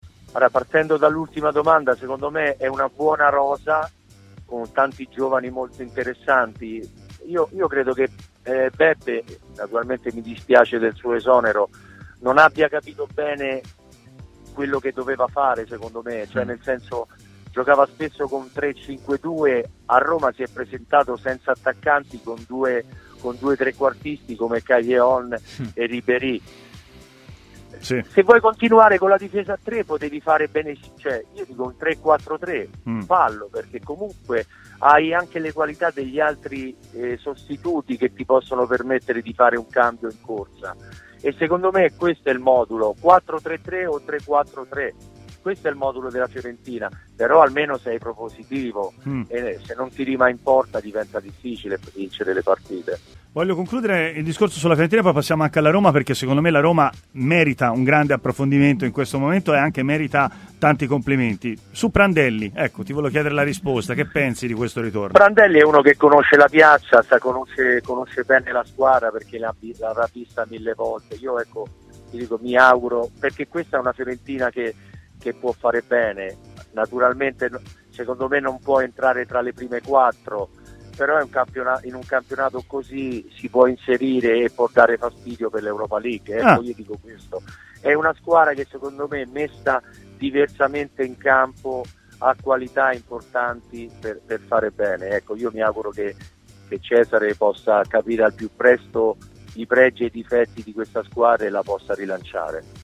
Intervistato da TMW Radio, Angelo di Livio ha parlato così della sua Fiorentina e del ritorno di Cesare Prandelli in panchina: "La Fiorentina?